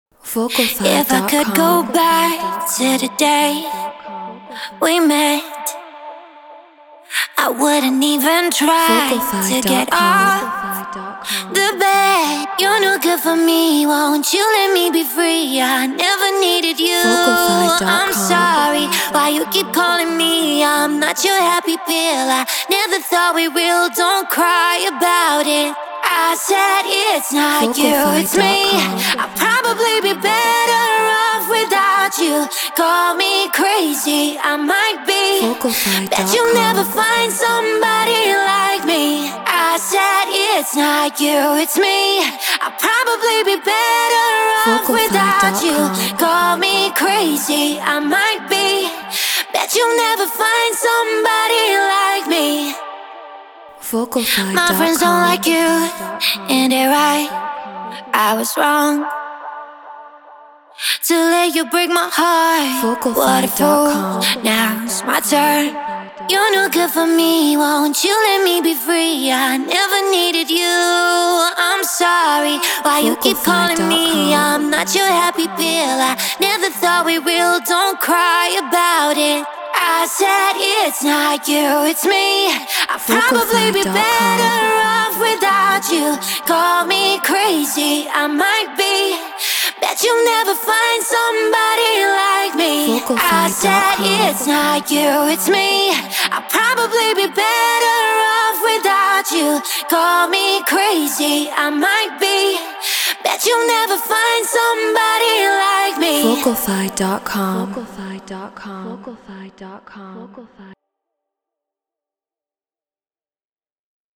Eurodance 143 BPM Dmin
Human-Made